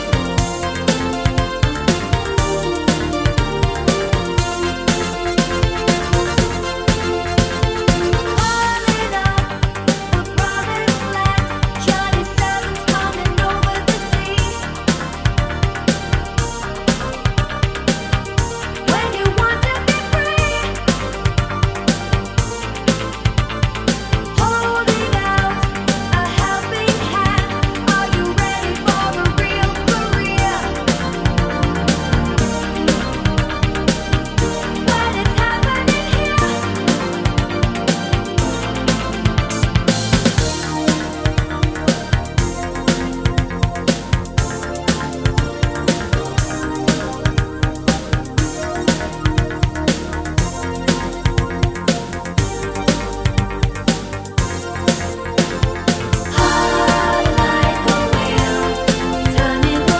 for solo male Pop (1980s) 4:19 Buy £1.50